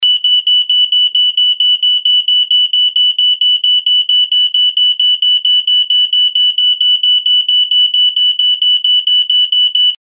hitzewarnmelder-warnton.mp3